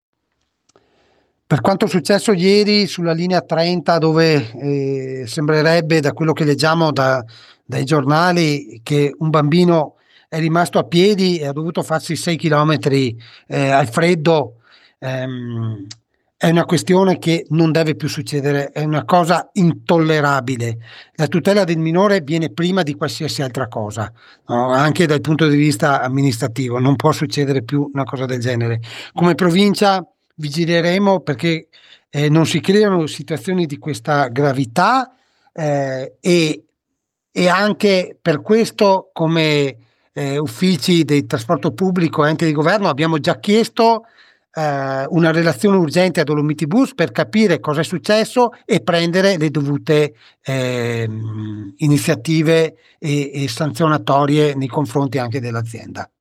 Massimo Bortoluzzi, consigliere provinciale con delega ai Trasporti, ha risposto assicurando di aver già chiesto spiegazioni a Dolomiti Bus S.p.A. e di aver avviato accertamenti. MASSIMO BORTOLUZZI, DELEGATO AI TRASPORTI DELLA PROVINCIA DI BELLUNO